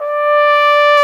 Index of /90_sSampleCDs/Roland L-CDX-03 Disk 2/BRS_Cornet/BRS_Cornet 2